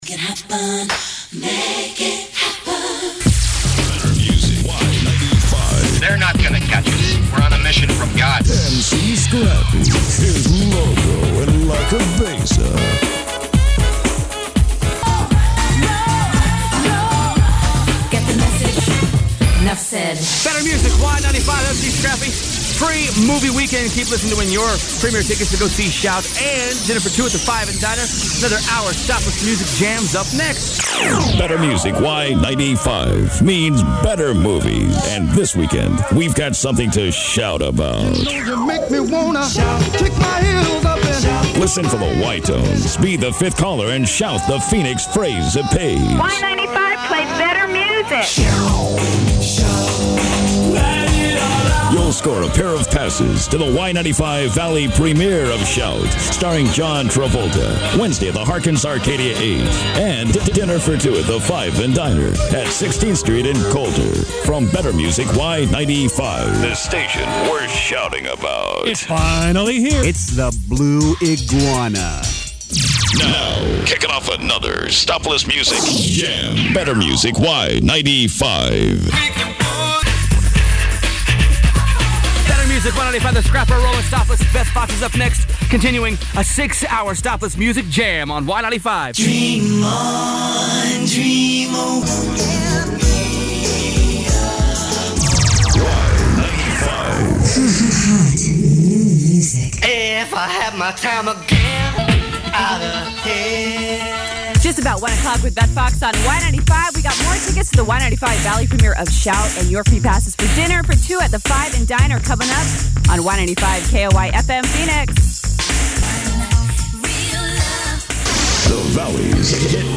This is an official station composite of the station from the Summer and Fall of 1991.